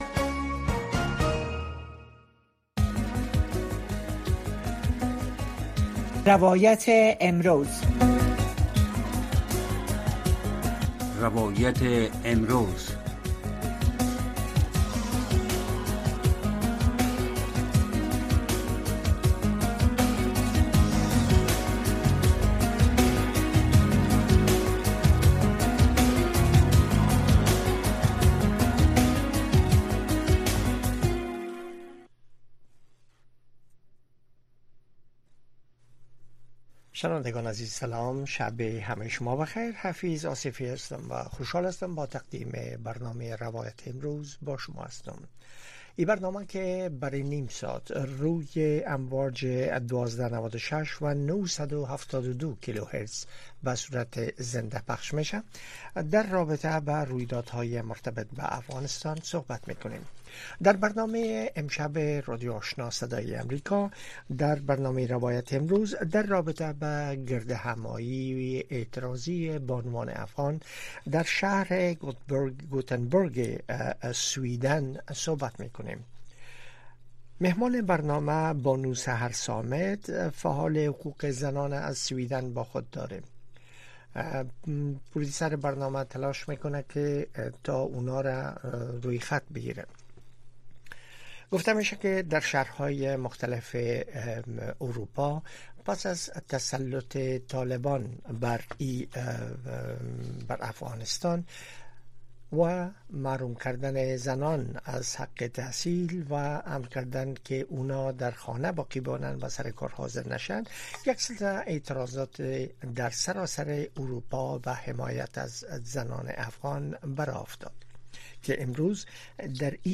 در برنامۀ روایت امروز شرح وضعیت در افغانستان را از زبان شهروندان و شرکت کنندگان این برنامه می‌شنوید. این برنامه هر شب از ساعت ٩:۳۰ تا ۱۰:۰۰ شب به گونۀ زنده صدای شما را پخش می‌کند.